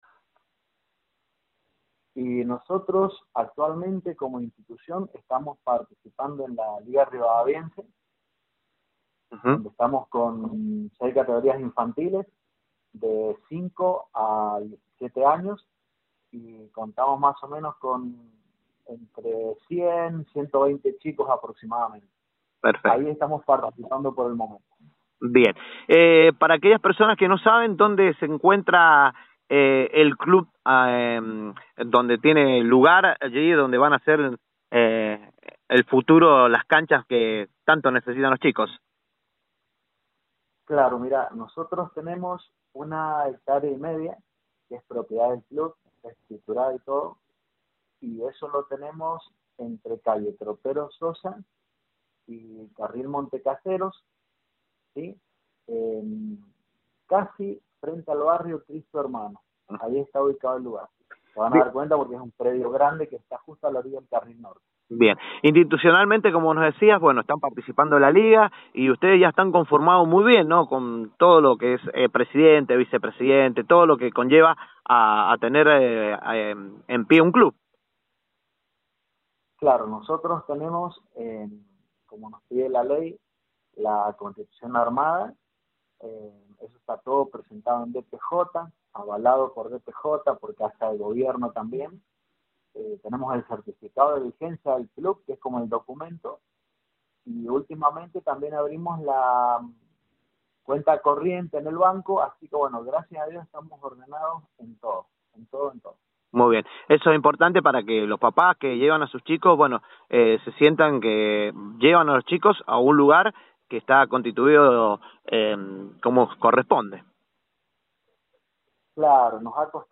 En la nota del día, en Proyección 103, nos metimos en la realidad de uno de los clubes locales que hoy militan en Liga Rivadaviense, y trabaja arduamente en la formación de sus jóvenes futbolistas.